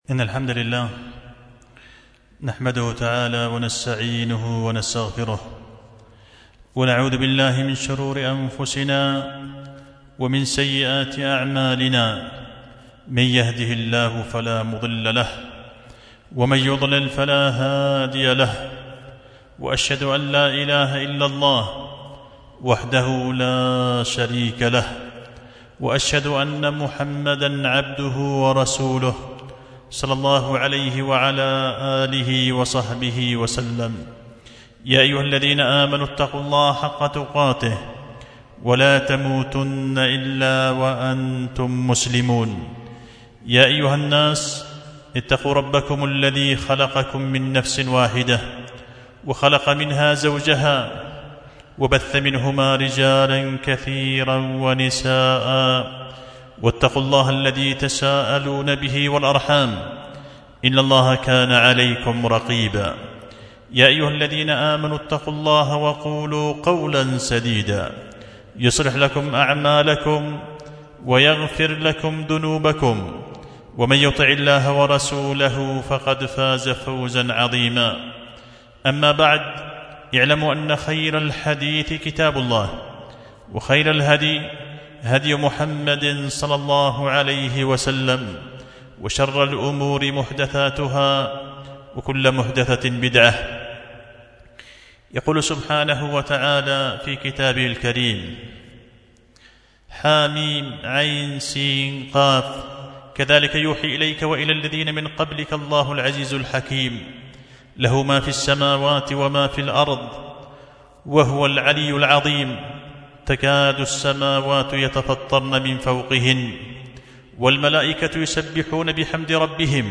خطبة جمعة بعنوان السبائك في بيان الأسباب التي ينال بها العبد دعاء الملائك